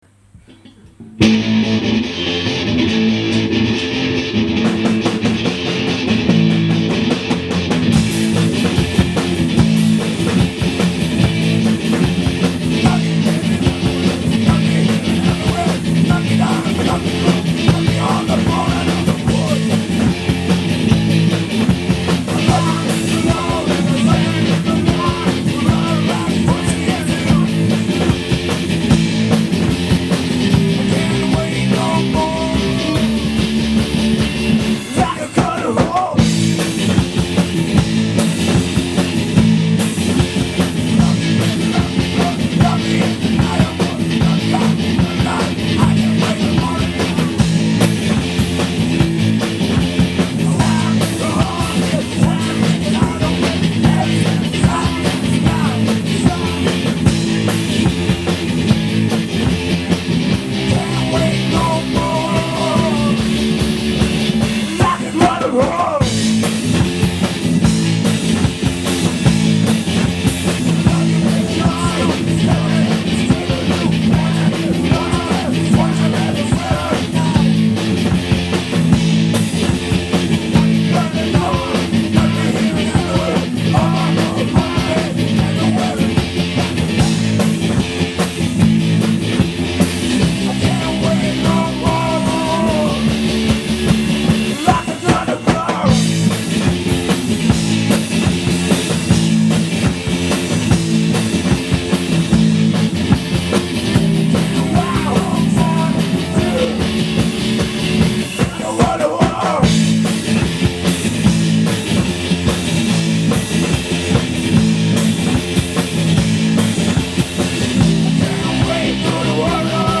gitarist
drummer